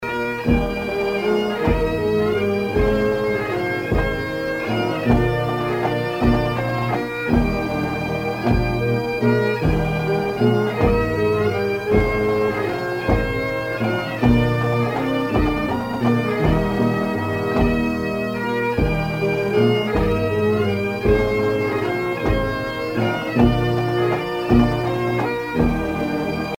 Fonction d'après l'analyste gestuel : à marcher